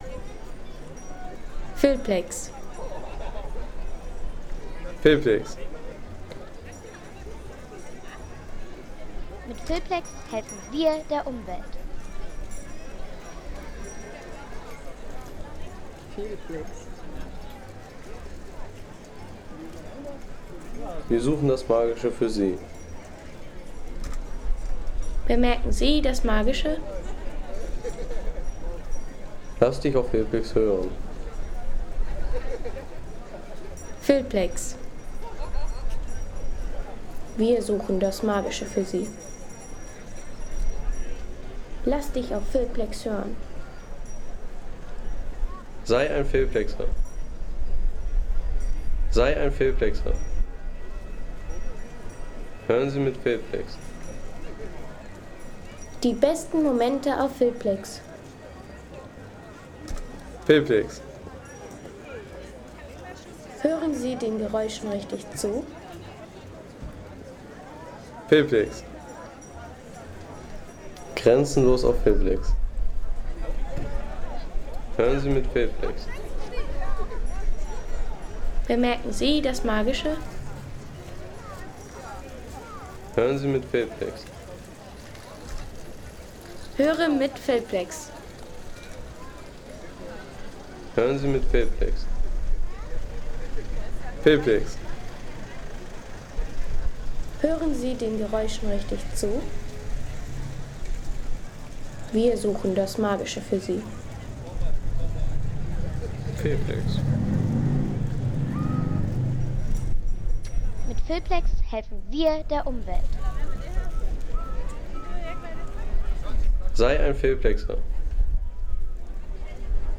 Weihnachtsmarkt in Flensburg